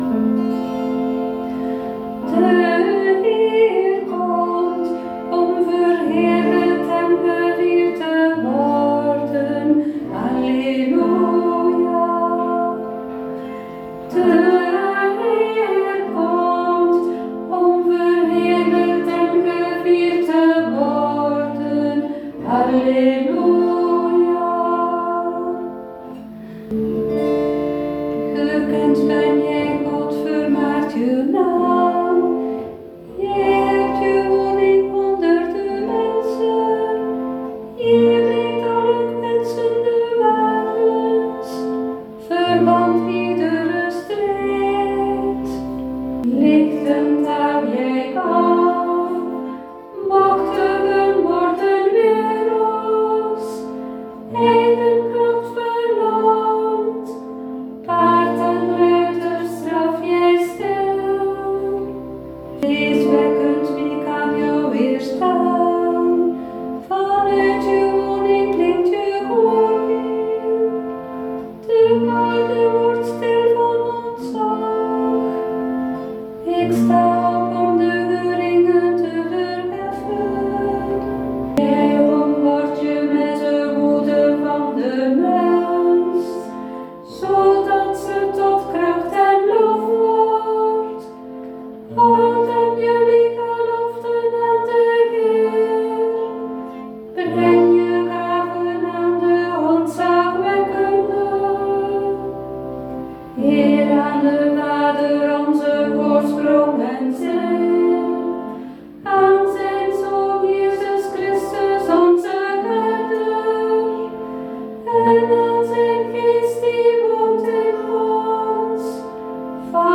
met citerbegeleiding